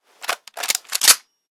20 KB I put the R91 reloadsounds together 1
Wpn_rifleassaultg3_reload.ogg